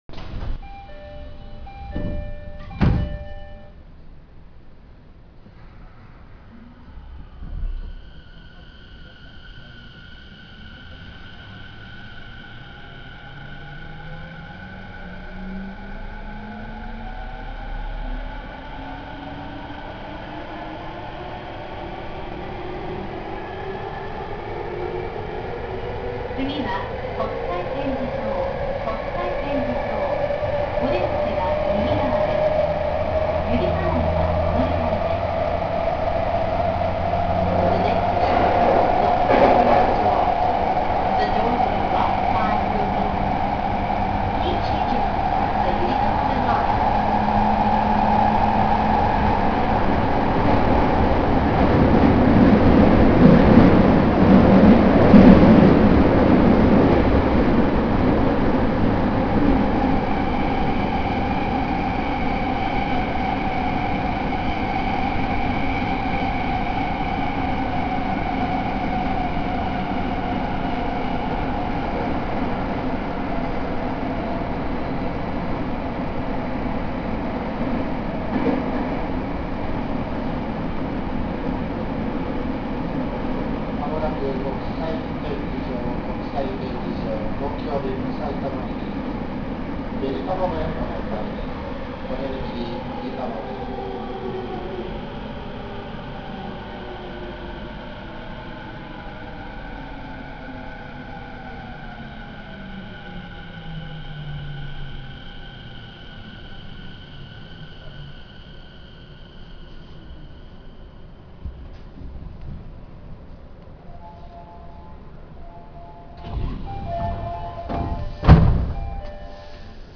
・70-000形三菱IGBT走行音
【りんかい線】東京テレポート〜国際展示場（2分00秒：653KB）
上記の通り、機器更新後も209系とモーター音は同じで、E233系に準じた三菱IGBTとなっています。